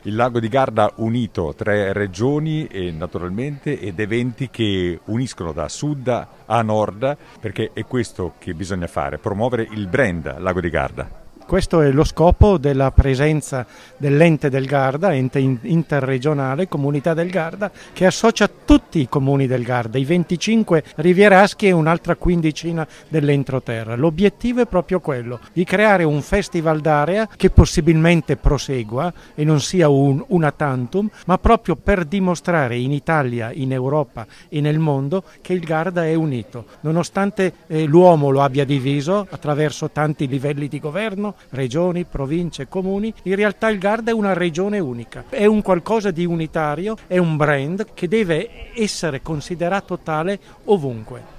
Le nostre interviste agli organizzatori della manifestazione: